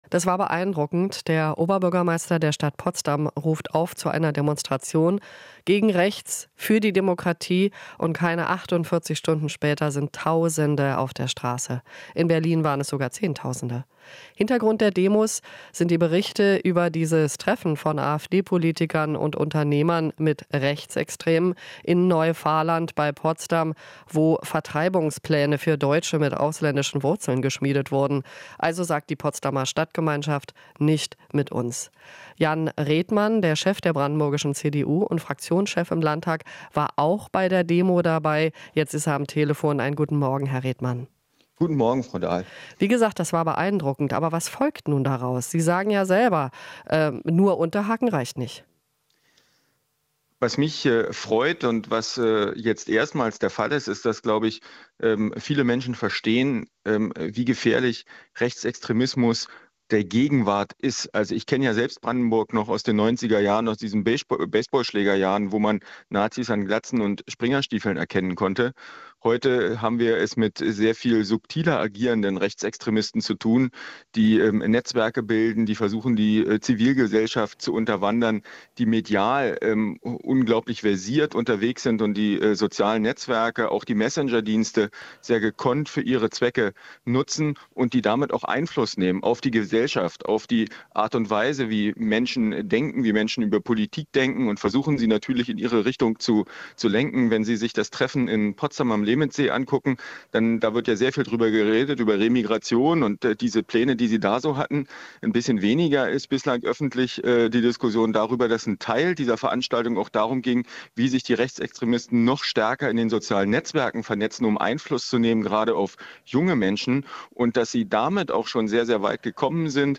Interview - Brandenburgs CDU-Chef Redmann ist gegen AfD-Verbot